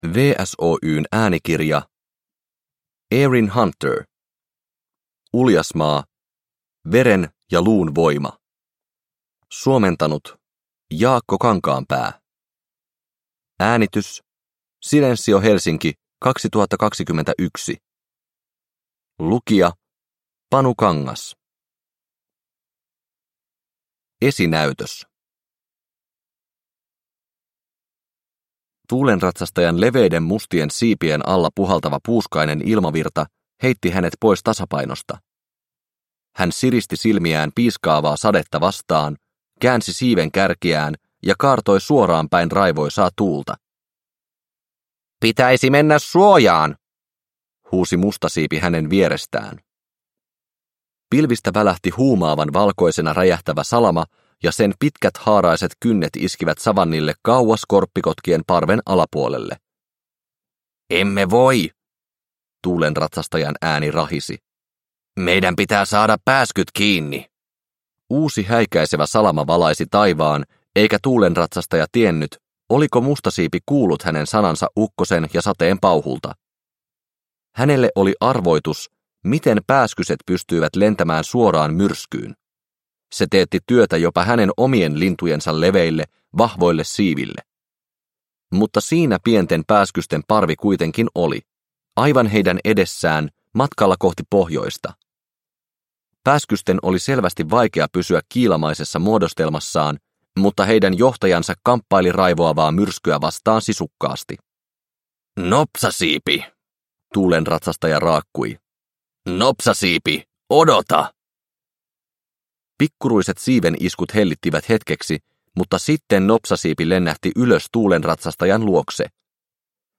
Uljasmaa: Veren ja luun voima – Ljudbok – Laddas ner